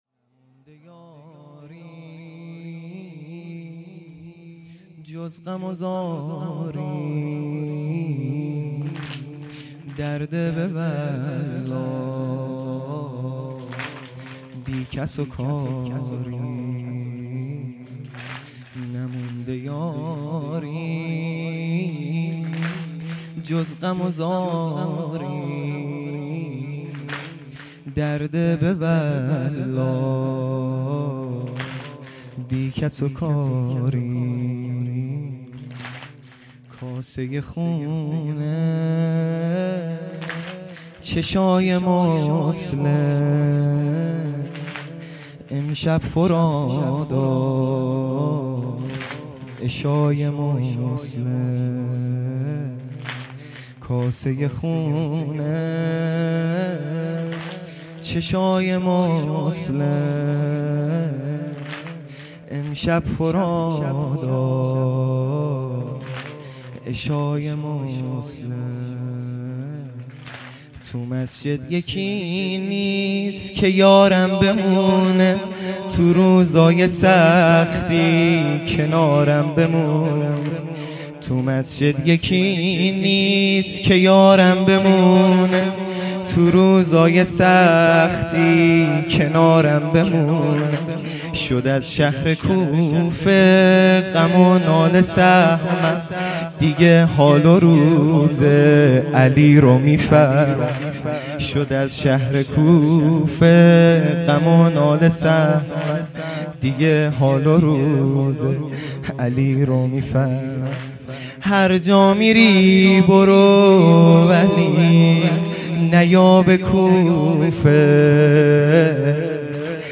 0 0 سنگین
شب اول محرم الحرام ۱۳۹۶